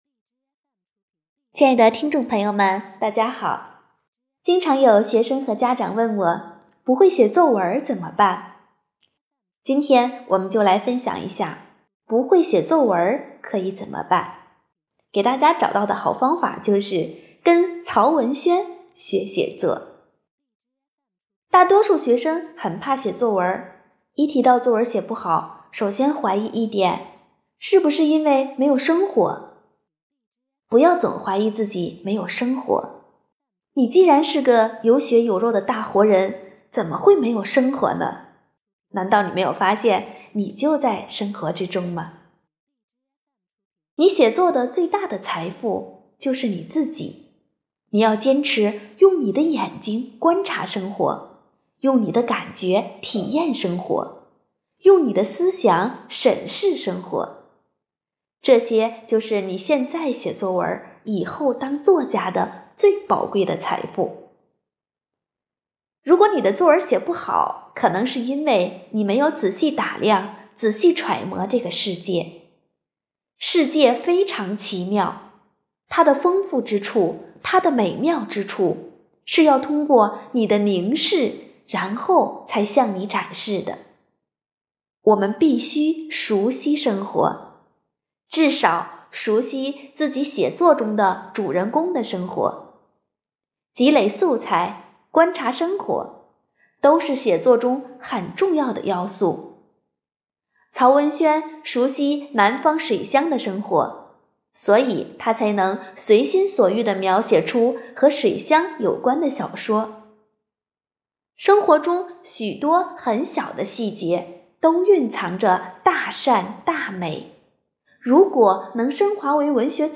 【讲座】丰图讲座 | 不会写作文怎么办？——跟曹文轩学写作
活动地点：丰南图书馆 线上活动